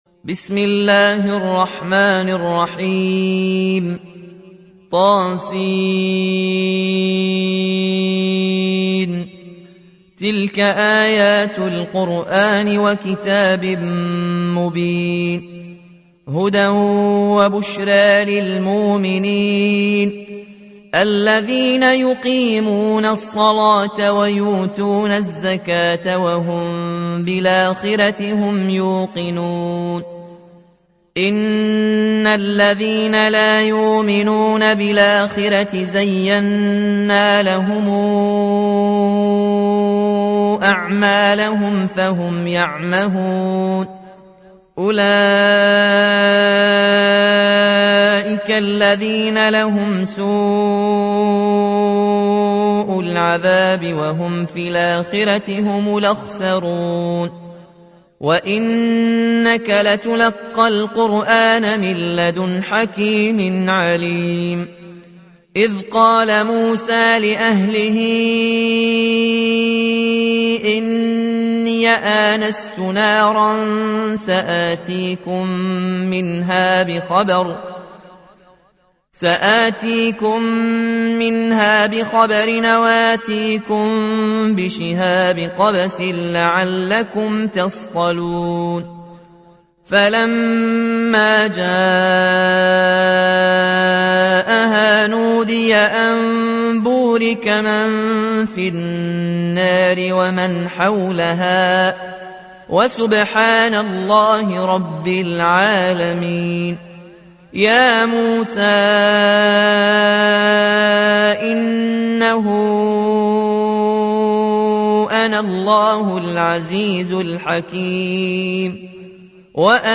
27. سورة النمل / القارئ